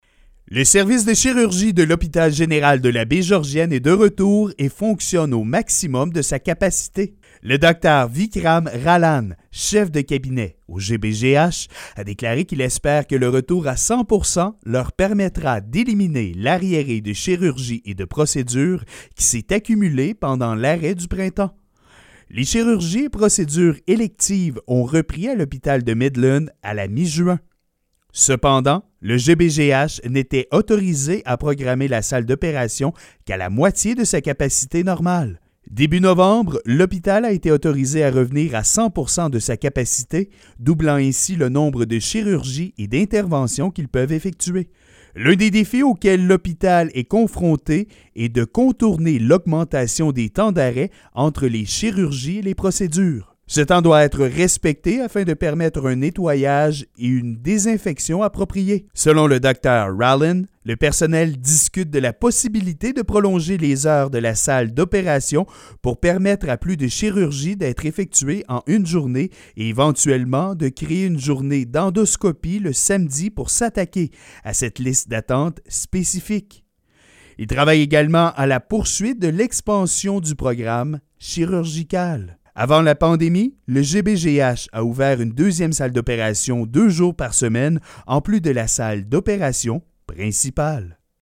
Voici les explications de notre journaliste: